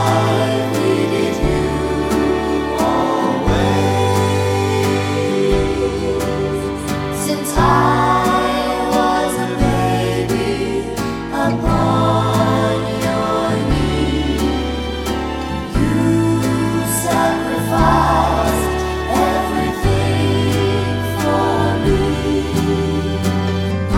no Backing Vocals Irish 3:31 Buy £1.50